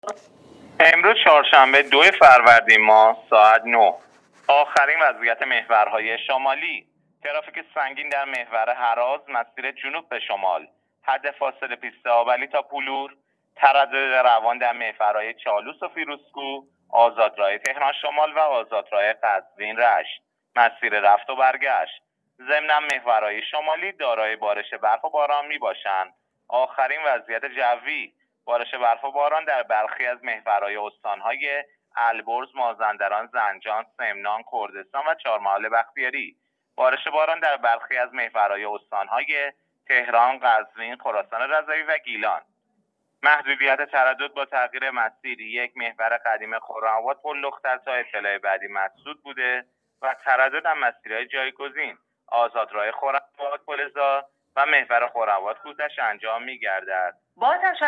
گزارش رادیو اینترنتی از آخرین وضعیت ترافیکی جاده‌ها تا ساعت ۹ دوم فروردین ماه؛